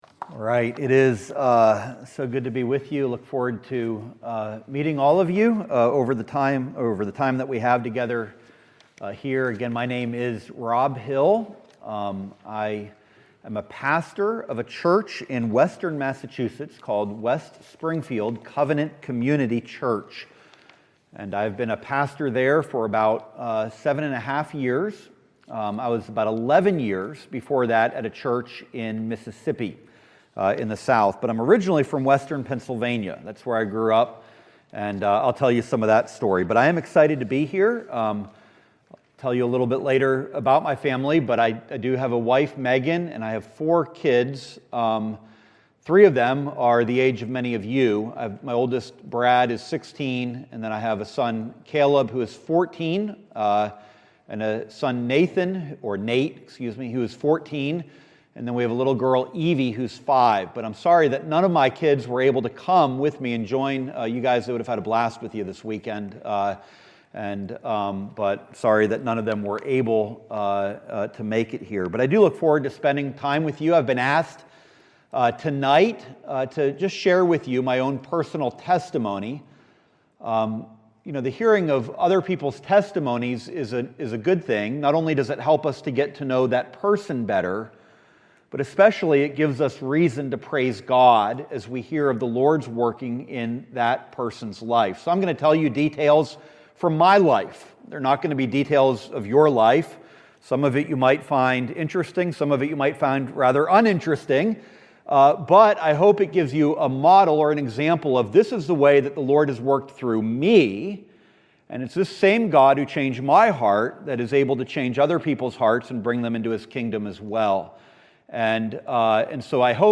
Personal Testimony of Pastor..